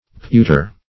Pewter \Pew"ter\ (p[=u]"t[~e]r), n. [OE. pewtyr, OF. peutre,